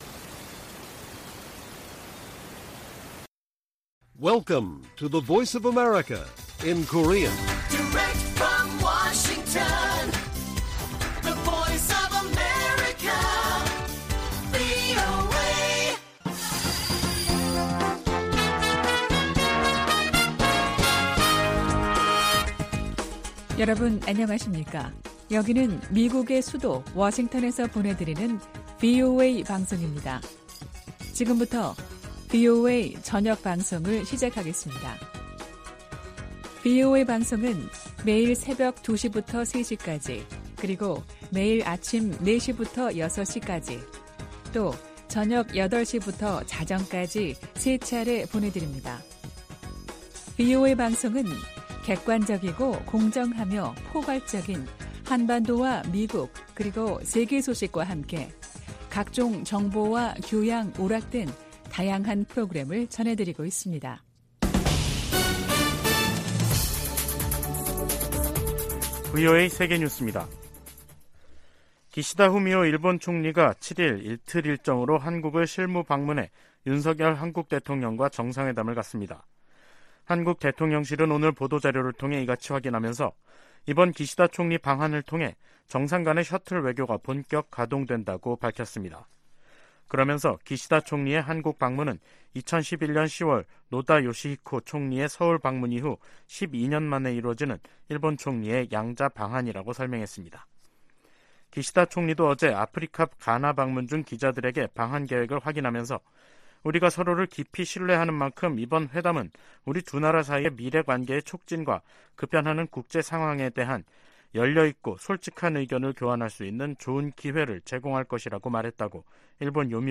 VOA 한국어 간판 뉴스 프로그램 '뉴스 투데이', 2023년 5월 2일 1부 방송입니다. 한일·미한일 정상회담이 이달 중 연이어 개최 될 예정입니다. 미 국무부는 워싱턴 선언은 북한의 핵 위협에 대한 미국의 억제력 강화 조치라며, 북한의 최근 비난을 일축했습니다. 미 국제종교자유위원회가 북한을 종교자유 특별우려국으로 재지정할 것을 권고했습니다.